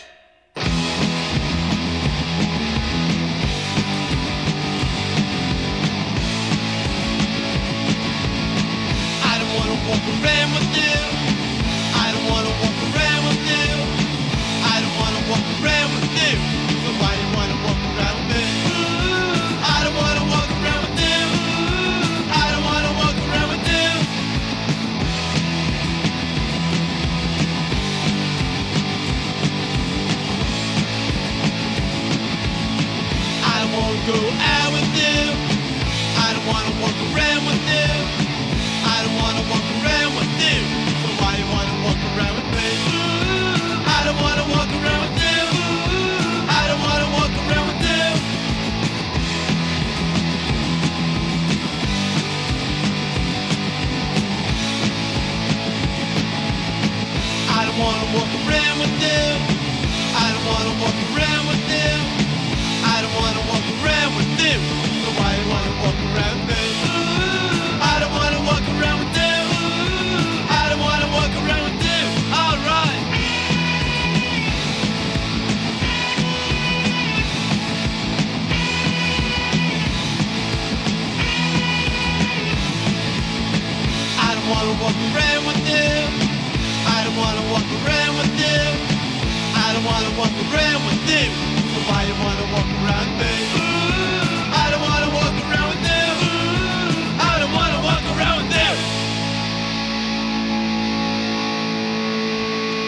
американская рок-группа